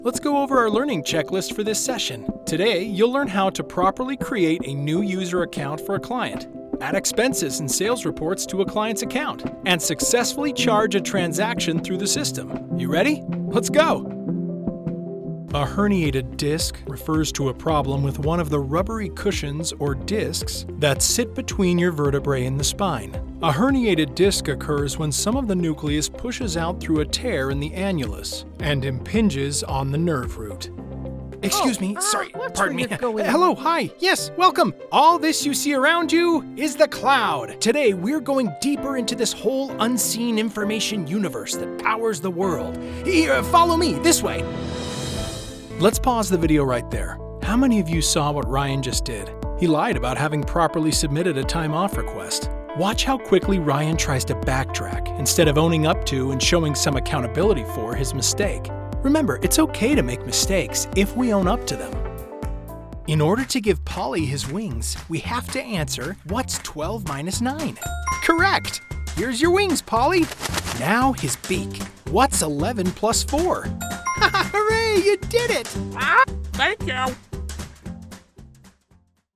Professionally-built, broadcast quality, double-walled LA Vocal Booth.